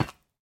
Minecraft Version Minecraft Version snapshot Latest Release | Latest Snapshot snapshot / assets / minecraft / sounds / block / bone_block / step3.ogg Compare With Compare With Latest Release | Latest Snapshot
step3.ogg